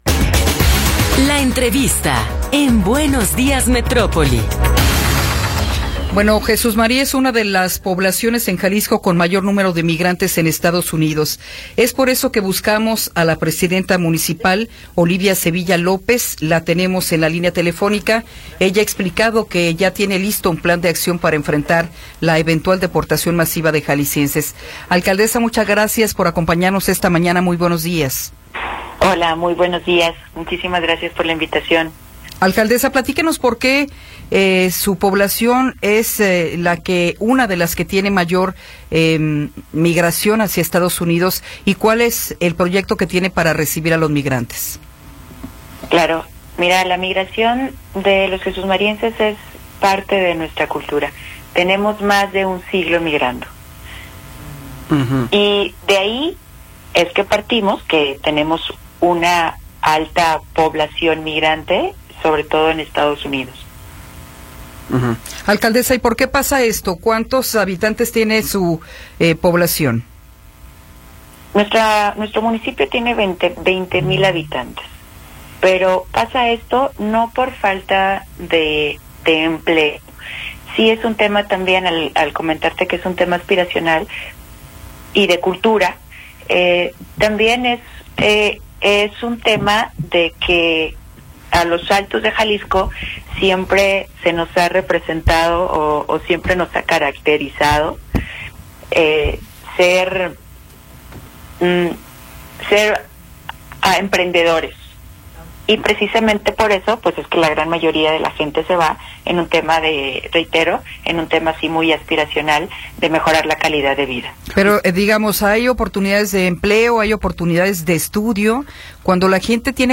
Entrevista con Olivia Sevilla López